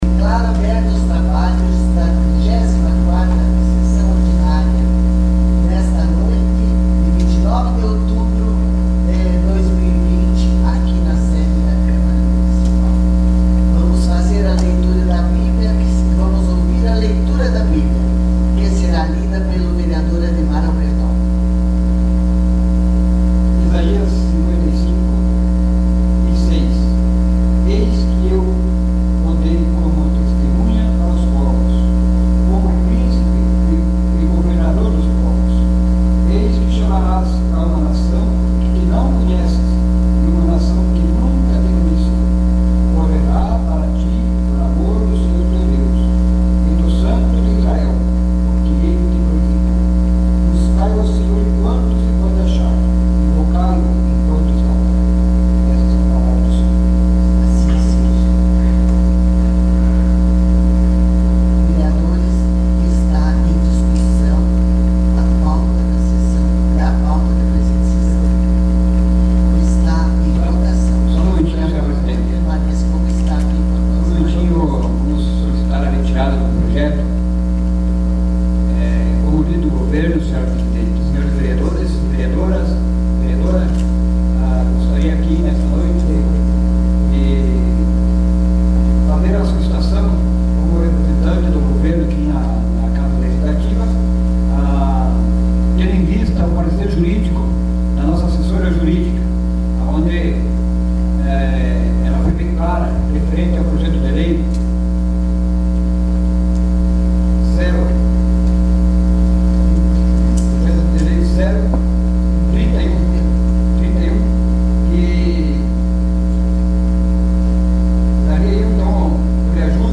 SESSÃO ORDINÁRIA DIA 29 DE OUTUBRO DE 2020